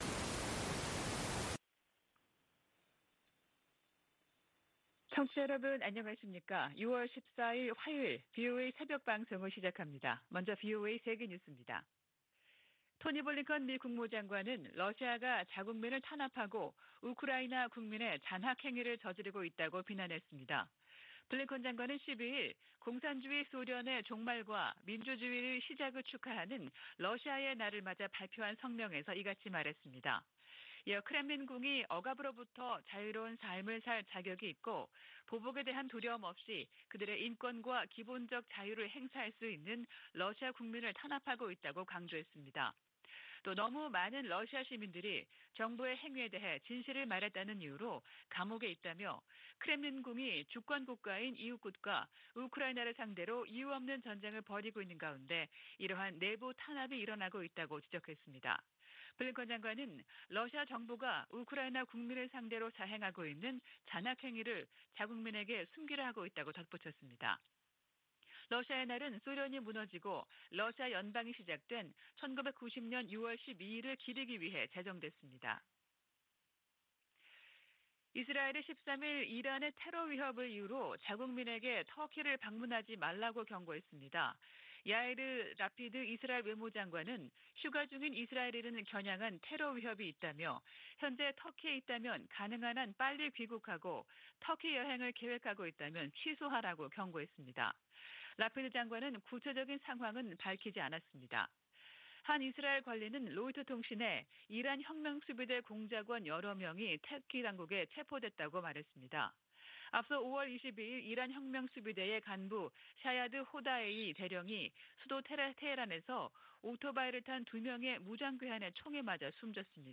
VOA 한국어 '출발 뉴스 쇼', 2022년 6월 14일 방송입니다.